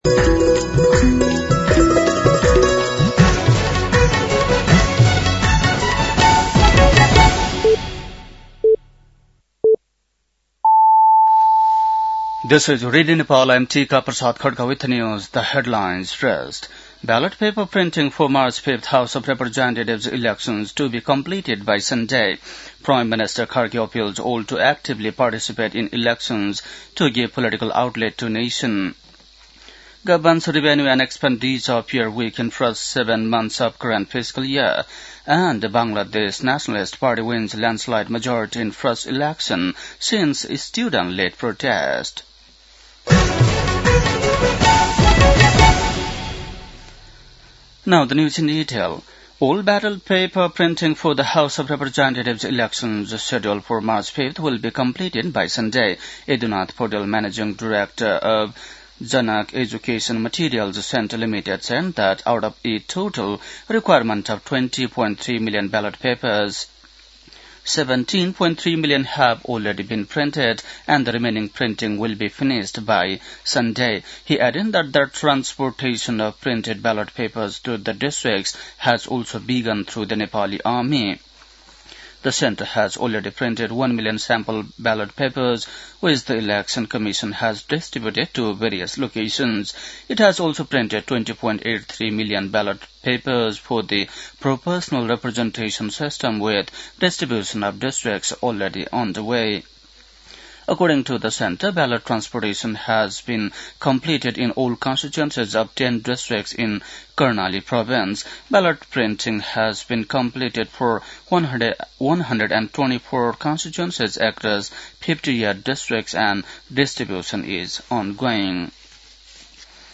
बेलुकी ८ बजेको अङ्ग्रेजी समाचार : १ फागुन , २०८२
8.-pm-english-news-1-4.mp3